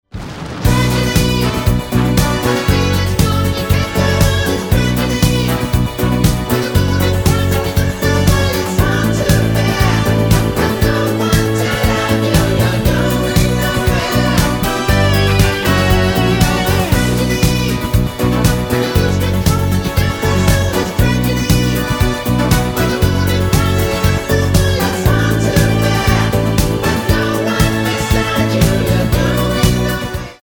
Tonart:Bm mit Chor